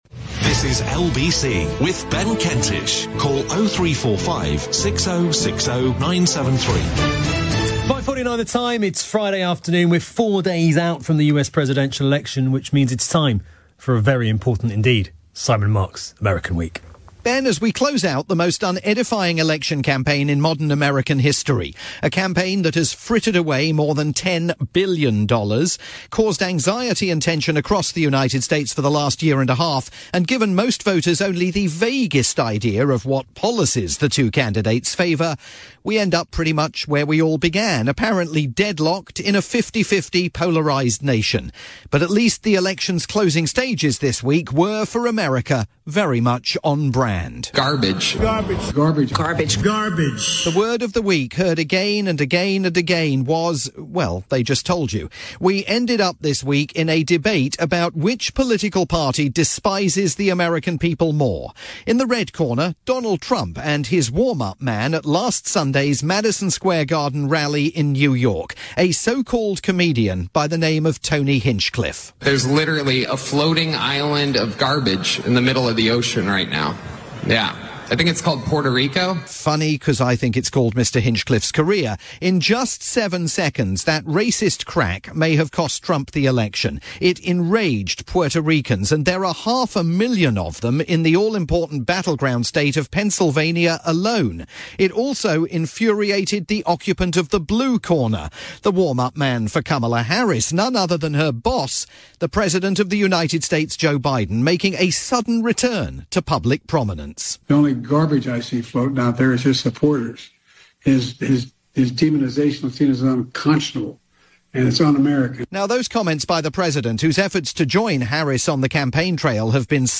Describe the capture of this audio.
Listen live every Friday at 5:50pm or find it on catch-up here afterwards.